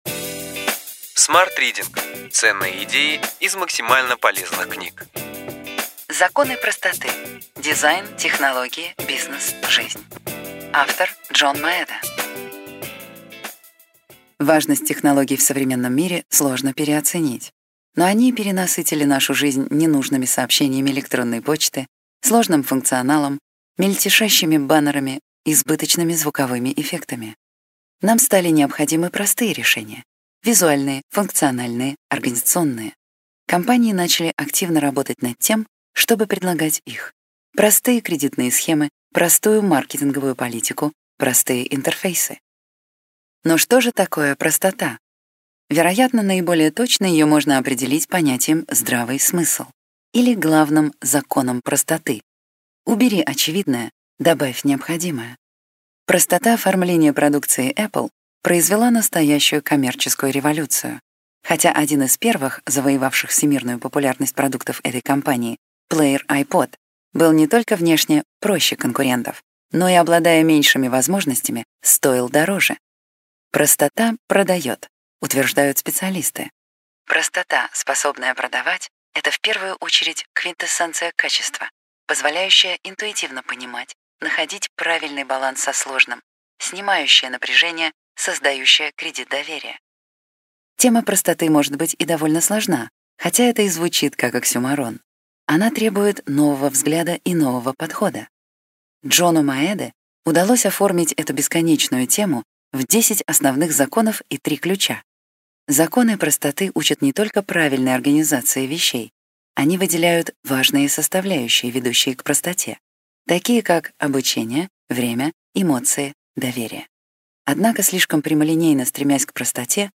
Аудиокнига Ключевые идеи книги: Законы простоты. Дизайн, Технологии, Бизнес, Жизнь. Джон Маэда | Библиотека аудиокниг